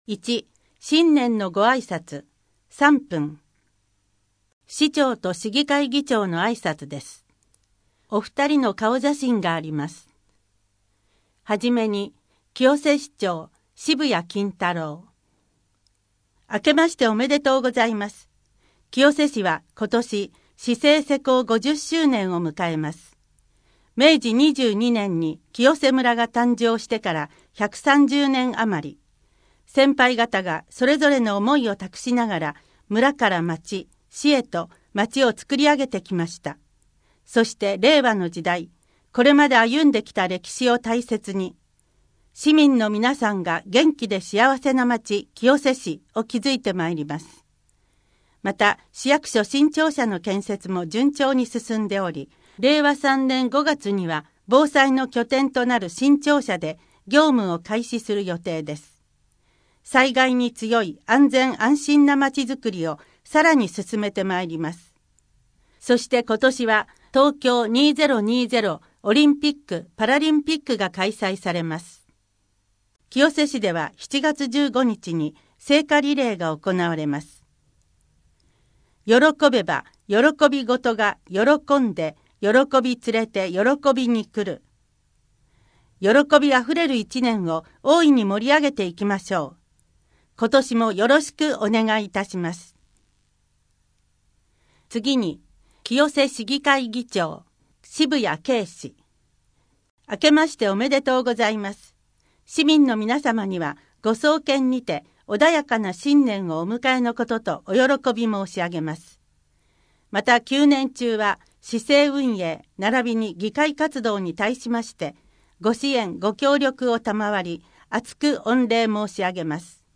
消費者向け キャッシュレス使い方講座 各館のイベント情報・お知らせ 郷土博物館からのお知らせ 児童館からのお知らせ まちかどニュース 「天皇陛下御即位をお祝いする国民祭典」で「清瀬上和太鼓」が演奏 1・2月の子育て関連事業 今月の健康づくり 1月の休日診療 声の広報 声の広報は清瀬市公共刊行物音訳機関が制作しています。